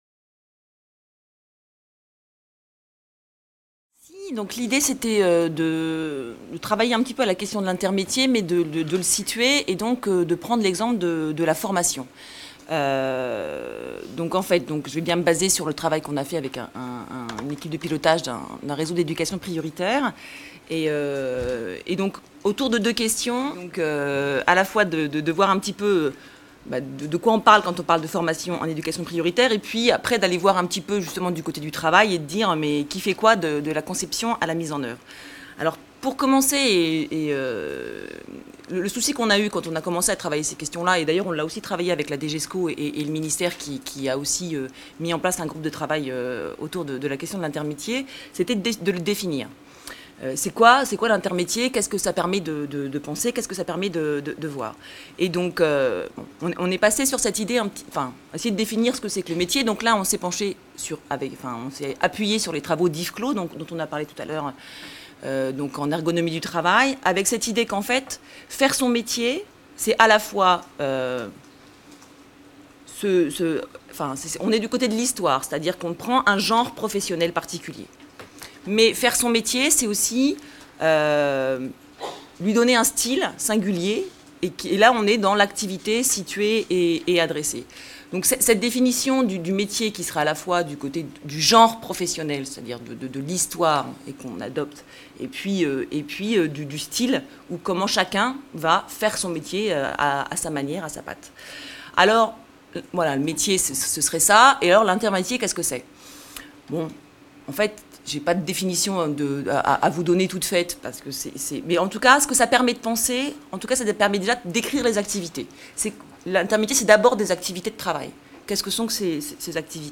La formation est une des dimensions du travail du coordonnateur et porte une dimension intermétier tant au niveau des collaborations à investir que des missions propres au coordonnateur. Une table-ronde a mis en scène les questions et les tensions à l'oeuvre pour le coordonnateur.